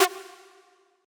synth2_18.ogg